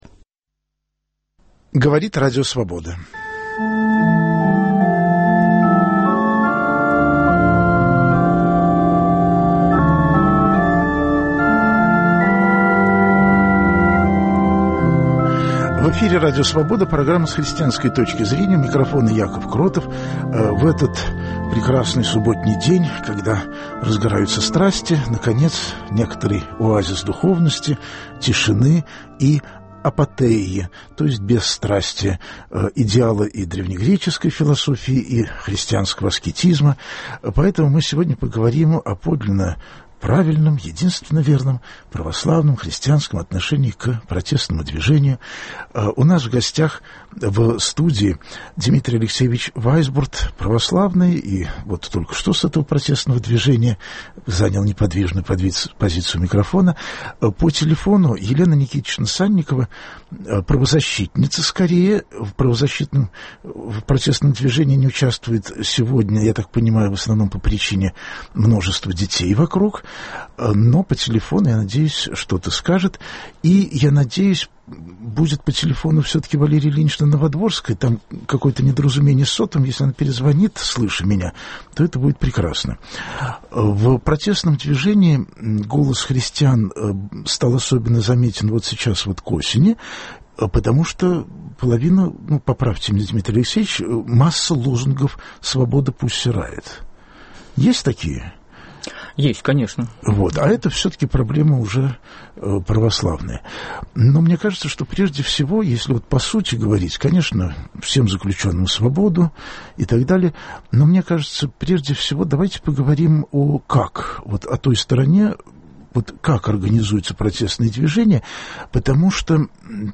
Эфир прямой, так что звоните прямо с марша миллионов или из глубины сердца единицы, спрашивайте, высказывайтесь